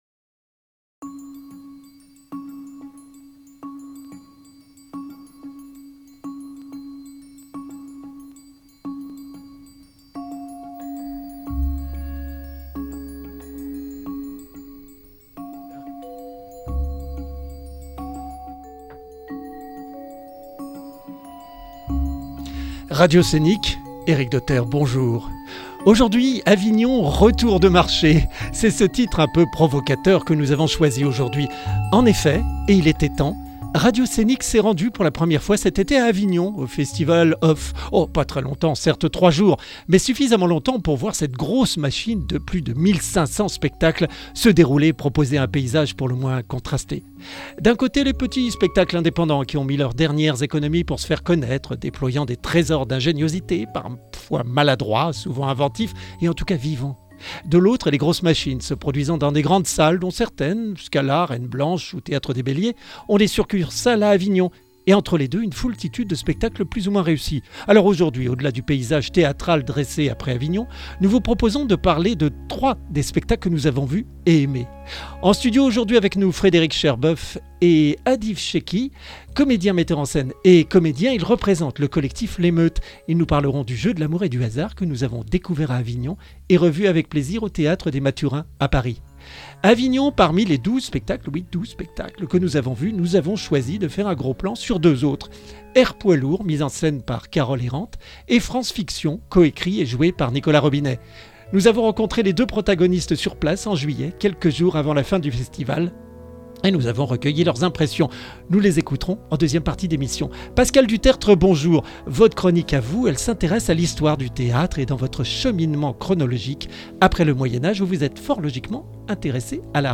Reportage et montage